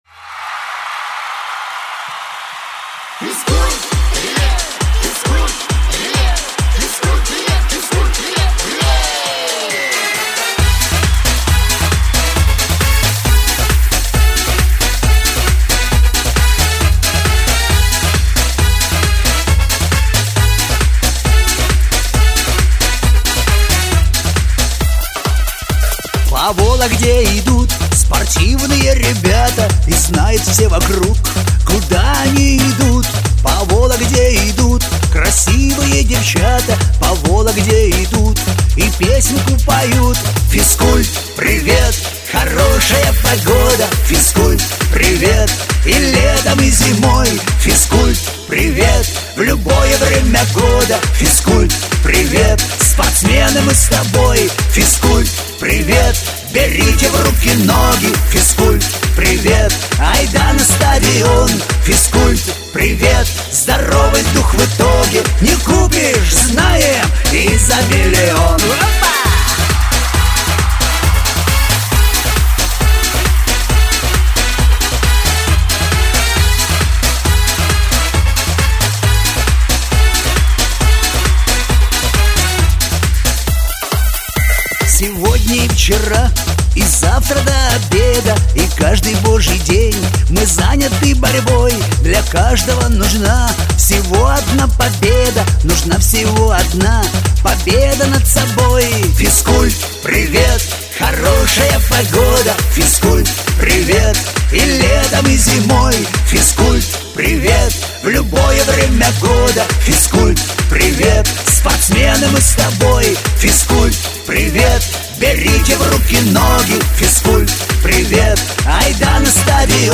• Категория: Детские песни
теги: зарядка, детский сад, малышковые